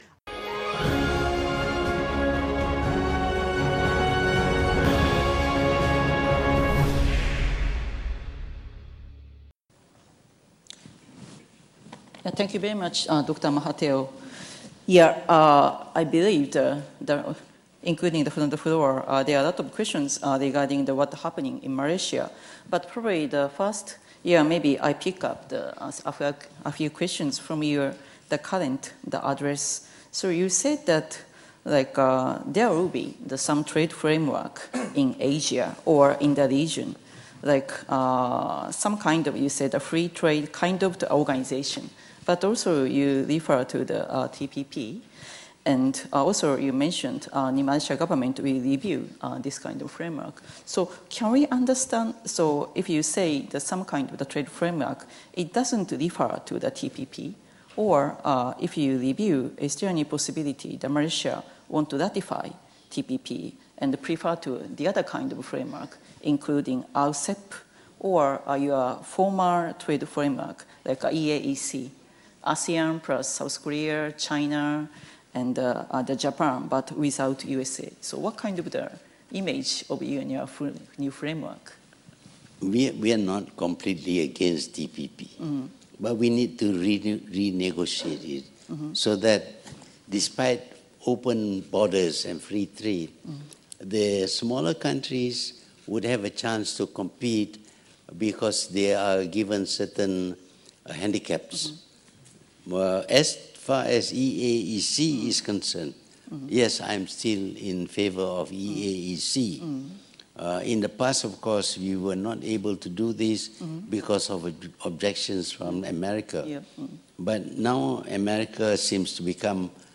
Ikuti rakaman penuh sesi dialog Perdana Menteri, Tun Dr. Mahathir Mohamad di Persidangan Antarabangsa Mengenai Masa Depan Asia di Tokyo, Jepun.